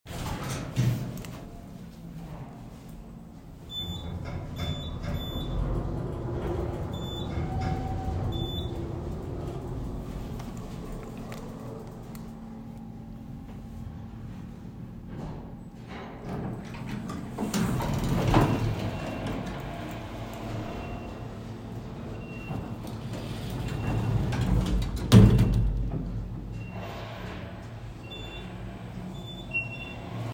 Elevator Noise - Attempt 2.m4a
closing college commercial-elevator ding door doors dorm-building elevator sound effect free sound royalty free Sound Effects